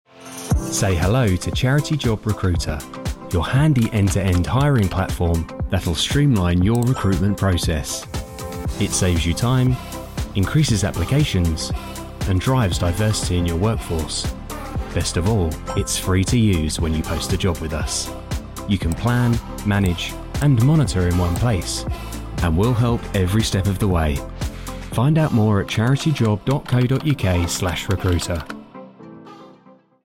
Product Promo – CharityJob Recruiter
BRITISH MALE VOICE-OVER ARTIST
Warm, neutral (non-regional) English accent
CharityJob-30s-Voiceover.mp3